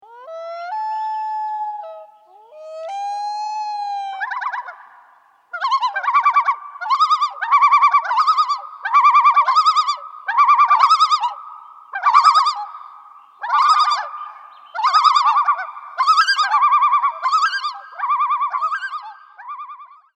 Loon call ]
Lake Ann, Michigan
Loon.mp3